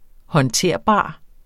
Udtale [ hʌnˈteɐ̯ˀˌbɑˀ ]